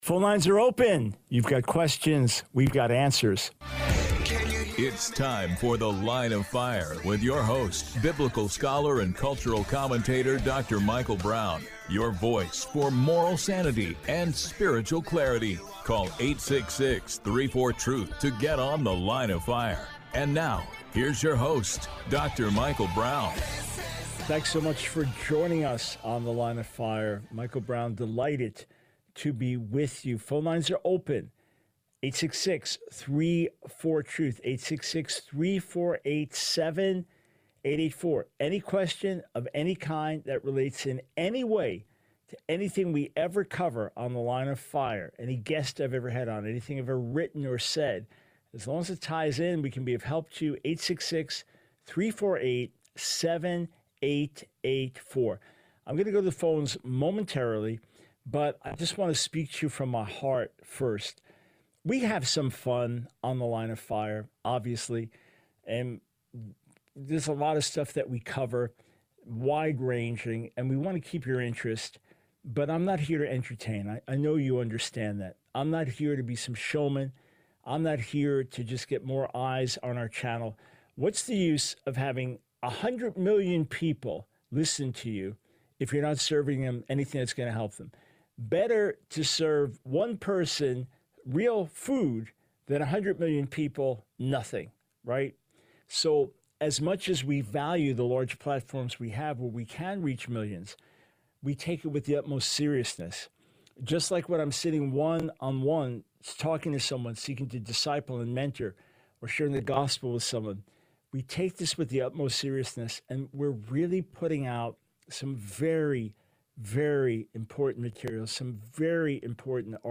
The Line of Fire Radio Broadcast for 08/30/24.